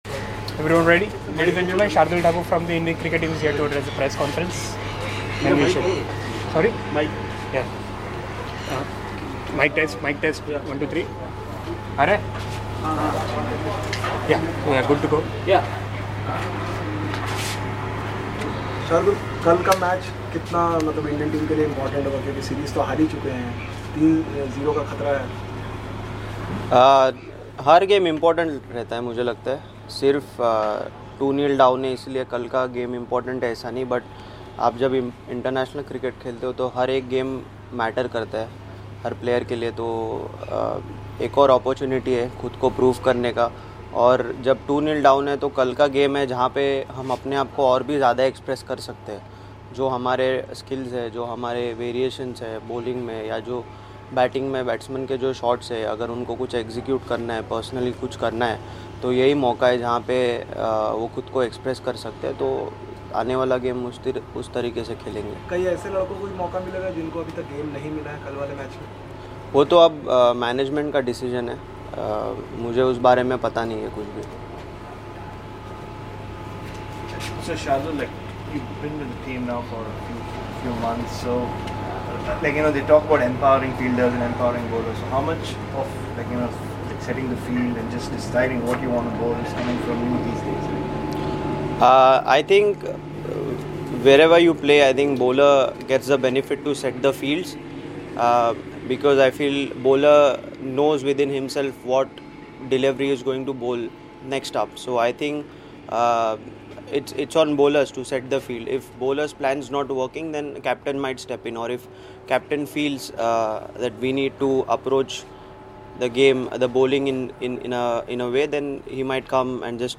Shardul Thakur, Member, Indian Cricket Team. He spoke to the media in Tauranga ahead of the 3rd ODI against New Zealand.